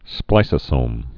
(splīsə-sōm)